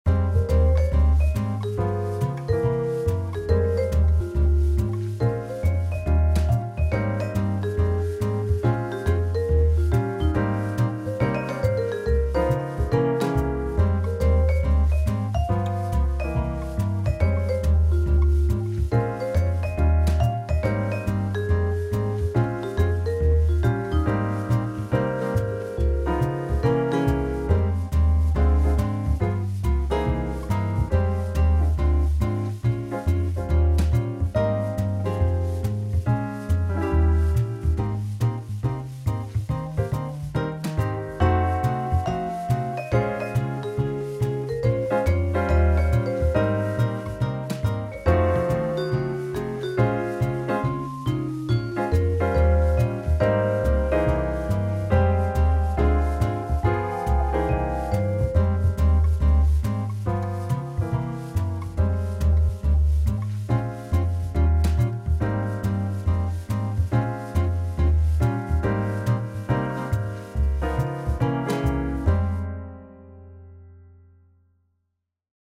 Jazz piece with guitar and piano
Jazz
Driving